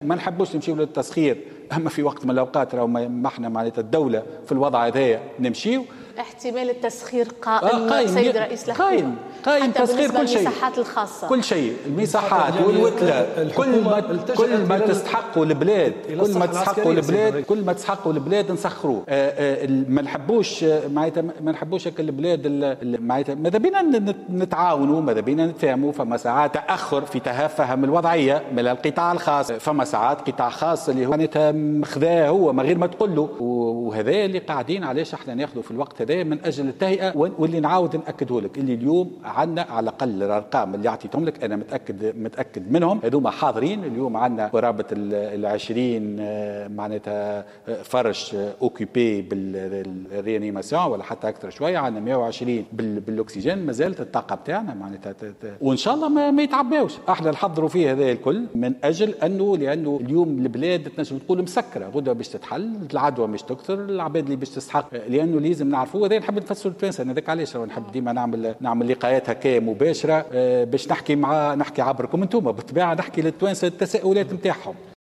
قال رئيس الحكومة في حوار تلفزي مباشر مساء اليوم الأحد أن إحتمال لجوء الدولة إلى التسخير يبقى قائما في كل المجالات من مصحات خاصة إلى نزل وكل ما تستحقه البلاد سيتم تسخيره.